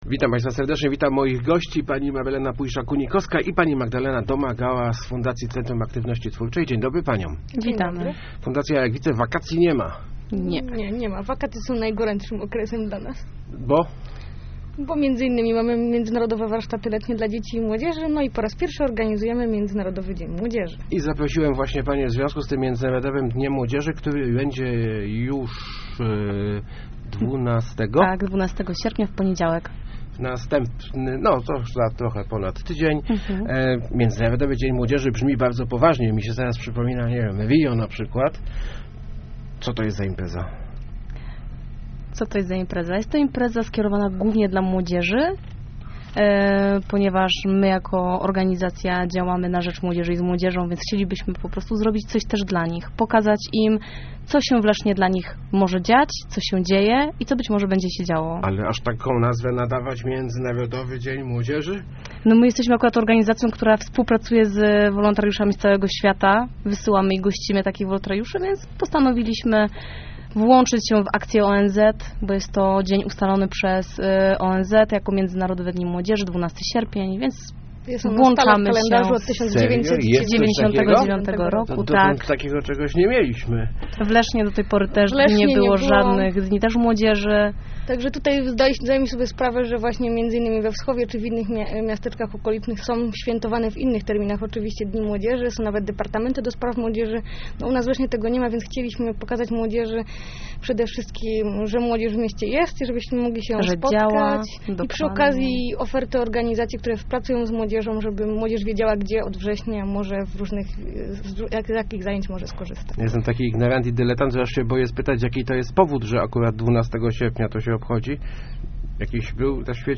Start arrow Rozmowy Elki arrow Międzynarodowy Dzień Młodzieży